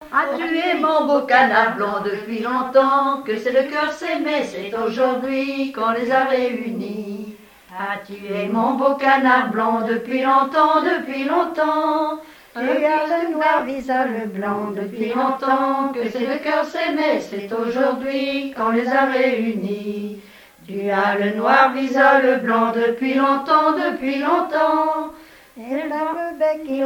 Genre laisse
Témoignages sur la pêche, accordéon, et chansons traditionnelles
Pièce musicale inédite